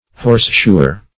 Search Result for " horseshoer" : Wordnet 3.0 NOUN (1) 1. a person who shoes horses ; [syn: farrier , horseshoer ] The Collaborative International Dictionary of English v.0.48: Horseshoer \Horse"sho`er\, n. One who shoes horses.